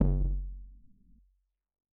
808s
[PBJ] Saint 808.wav